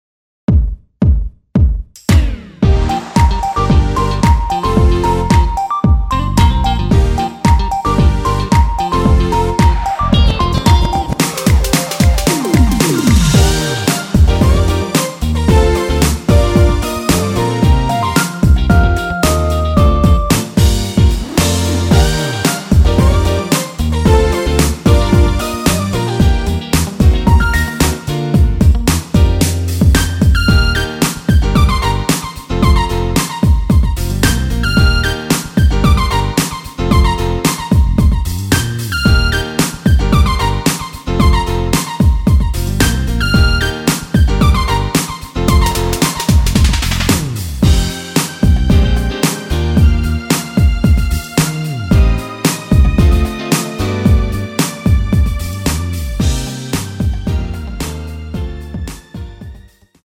MR 입니다.
Bb
앞부분30초, 뒷부분30초씩 편집해서 올려 드리고 있습니다.
중간에 음이 끈어지고 다시 나오는 이유는